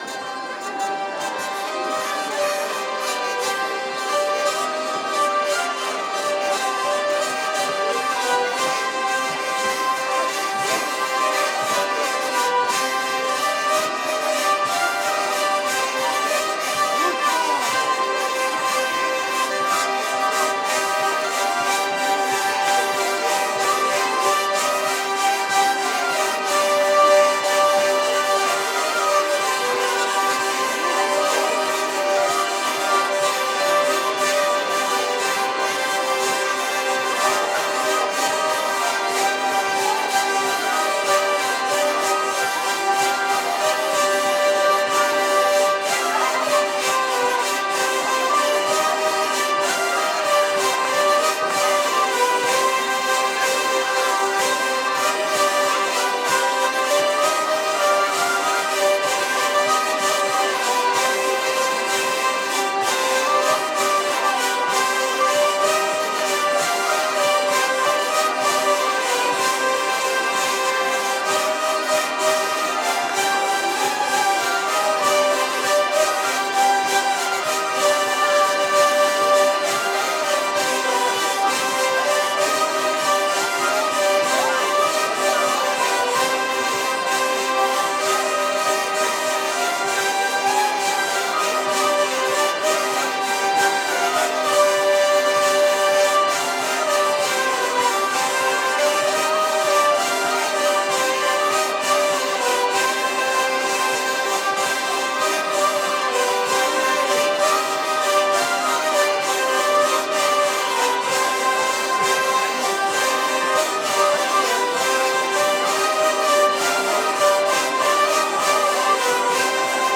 01_valse-vielles.mp3